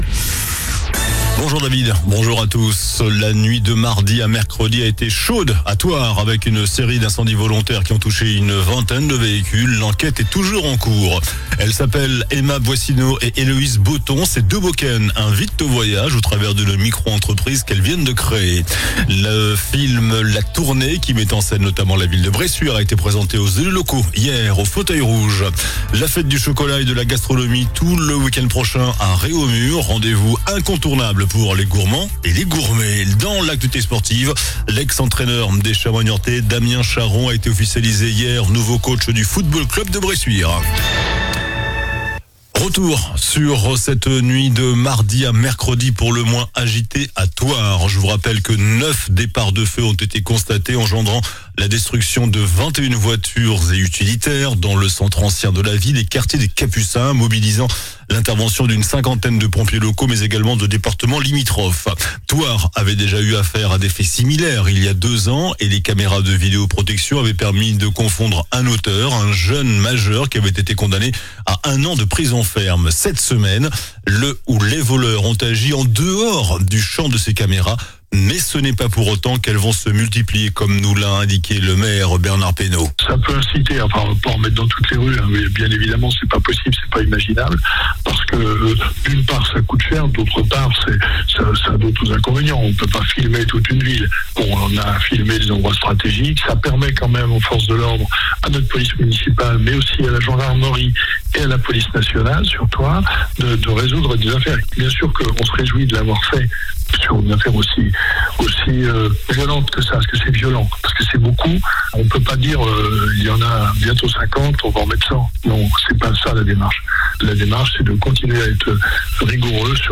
JOURNAL DU JEUDI 24 AVRIL ( MIDI )